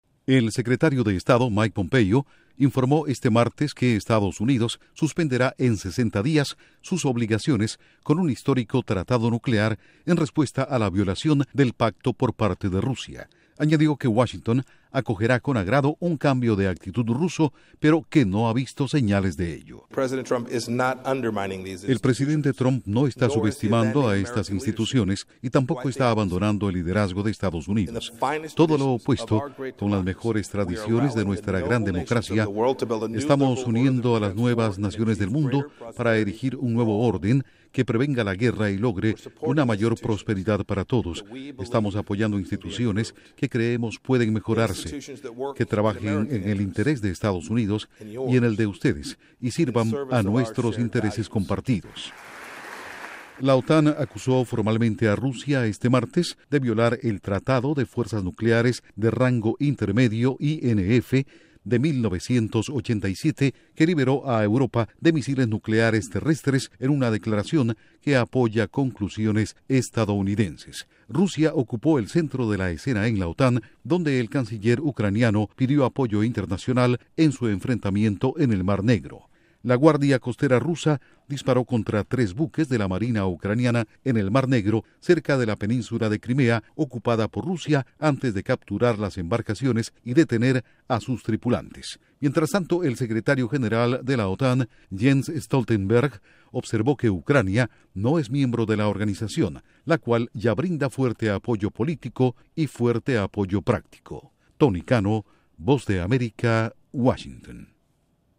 Duración: 1:48 audios de Mike Pompeo/Secretarrio de Estado